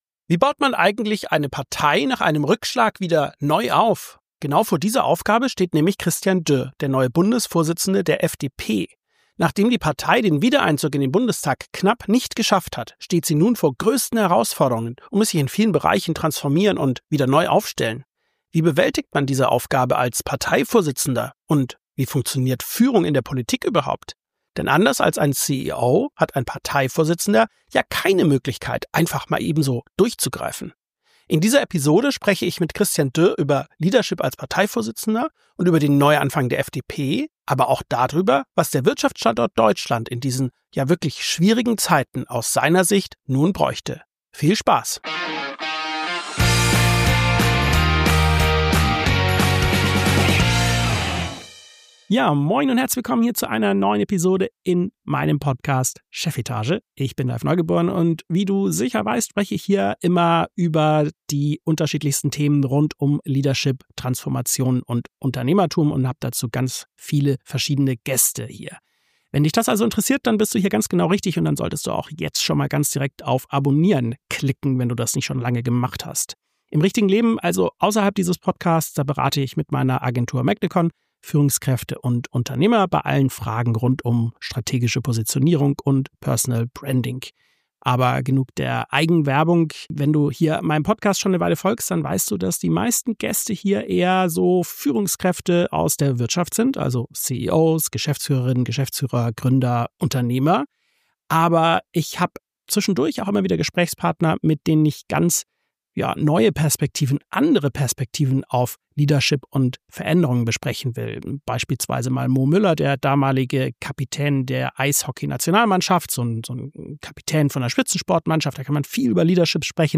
In dieser Episode spreche ich mit ihm darüber, wie er seine Rolle als FDP-Vorsitzender versteht.